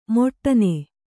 ♪ moṭṭane